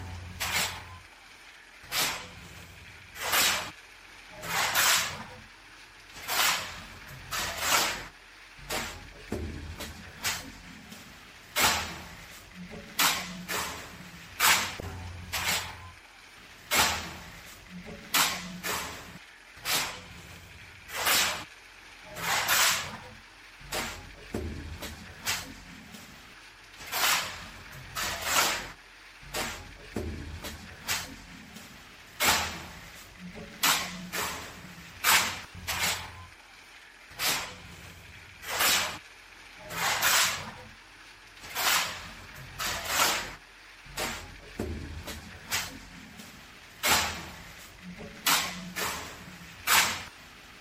Tiếng Trộn Hồ xây dựng bằng tay [Mẫu 2]
Thể loại: Tiếng động
Description: Tiếng trộn hồ xây dựng bằng tay [Mẫu 2] tái hiện âm thanh chân thực, truyền thống của thợ xây: sột soạt,xáo trộn, khuấy đều, lạo xạo, sần sật, lạo xạo khi xẻng lùa vào hỗn hợp xi măng, cát, nước. Sound effect sống động, mộc mạc, gợi hình ảnh lao động miệt mài, phù hợp làm hiệu ứng âm thanh cho video, phim về xây dựng...
tieng-tron-ho-xay-dung-bang-tay-mau-2-www_tiengdong_com.mp3